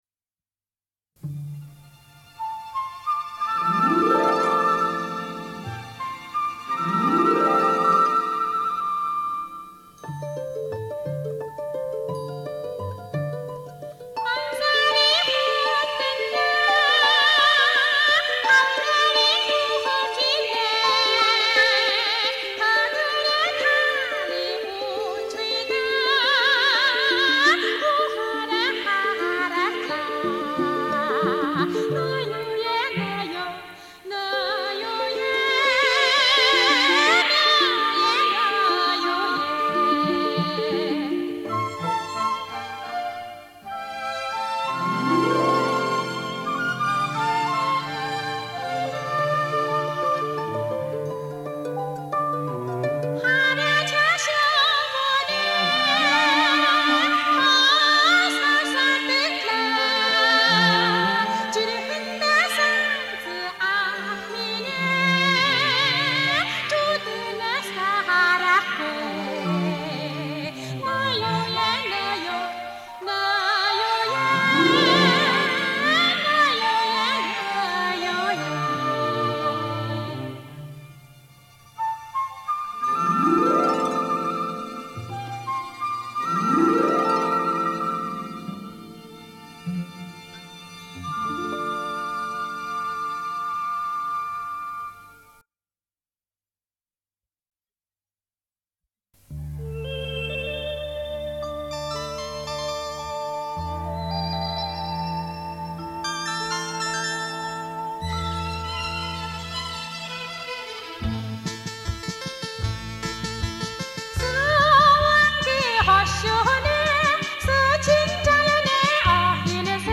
蒙古语演唱